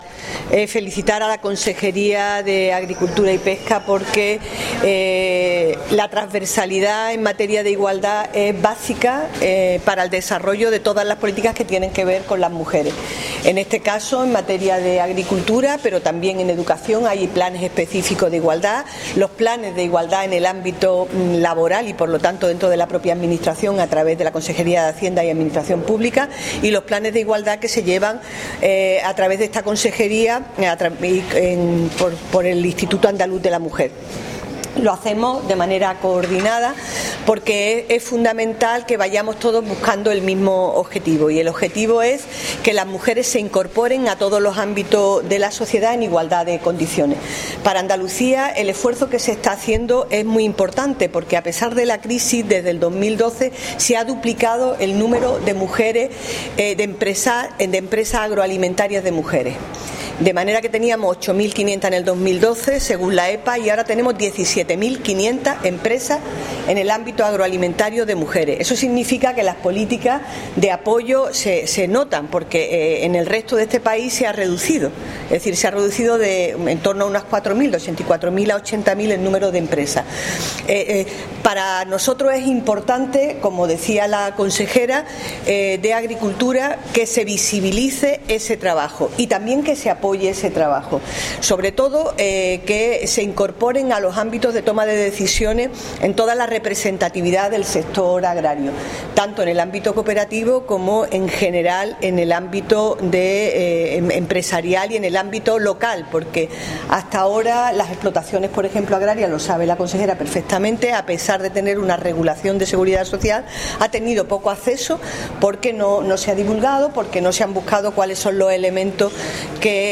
Declaraciones de María José Sánchez Rubio sobre el I Foro Nacional Business Agro ‘Mujeres agroprofesionales. Por la igualdad de oportunidades’